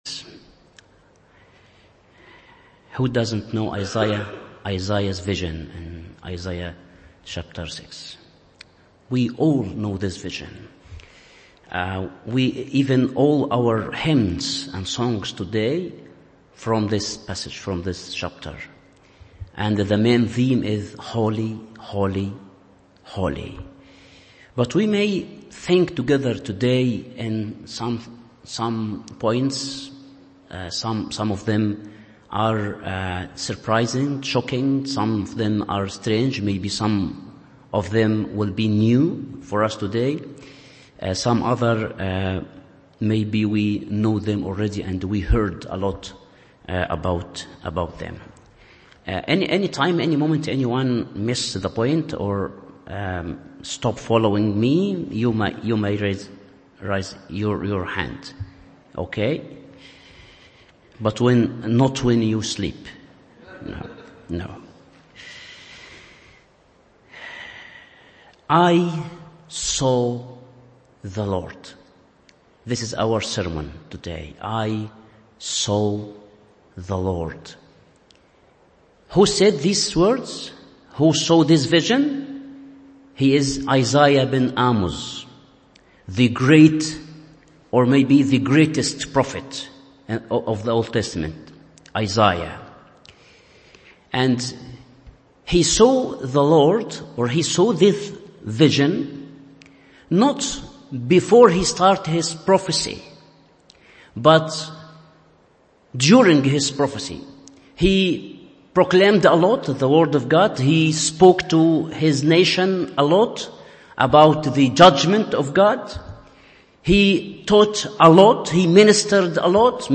Morning Service Isaiah 6:1-13 1. I saw my God 2. I saw myself 3. I saw my mission…